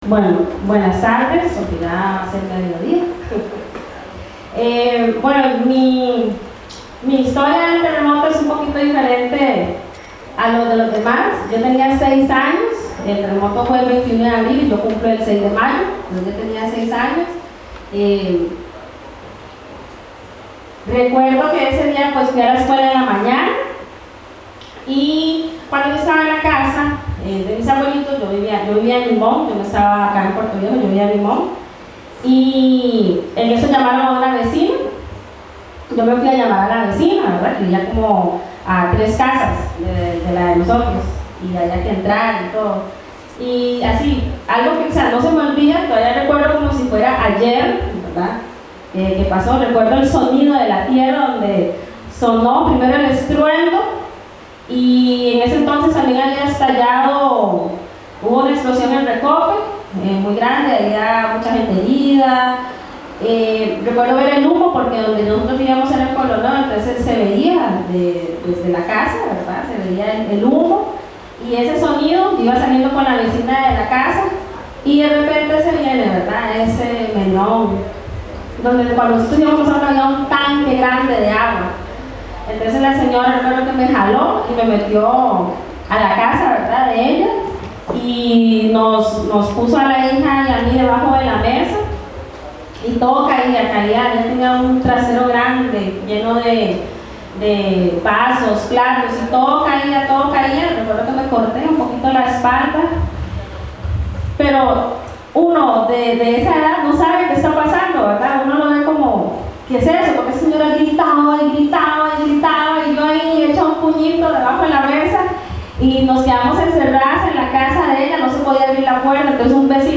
Local storytellers recounted their experience of the 7.7 M earthquake that shook Costa Rica's Caribbean coast in 1991, leaving the landscape transformed and marking an important moment in the affected communities' histories. These recordings were made at a special storytelling event for local school children hosted by Casa de la Cultura Puerto Viejo, the Center of Documentation and Information (CNE) and the Rich Coast Project on the 20th anniversary of the 1991 earthquake.